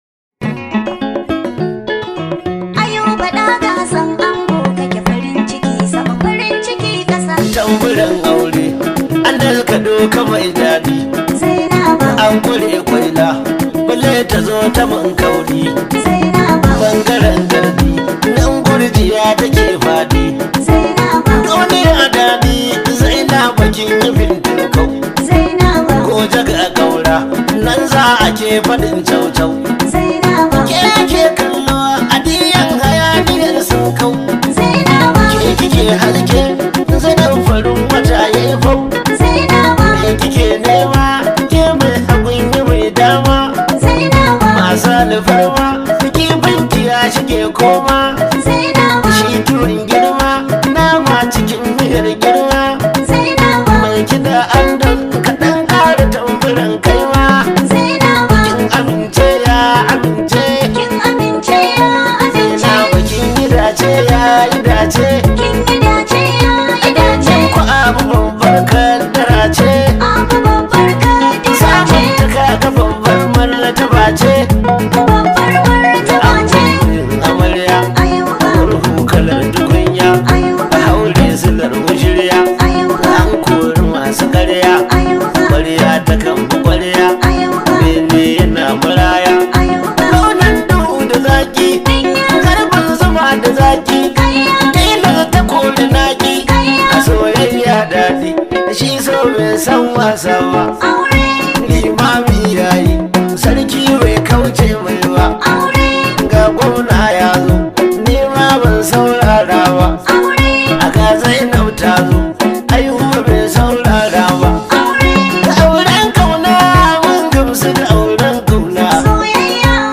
high vibe hausa song